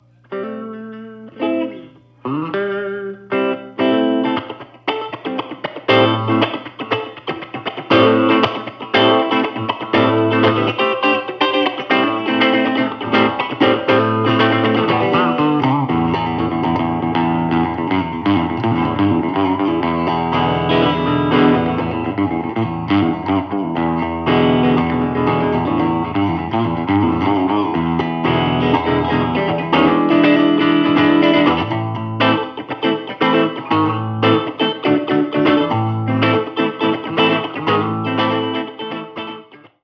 These were taken straight from mic-to-recording, with no processing.
Funky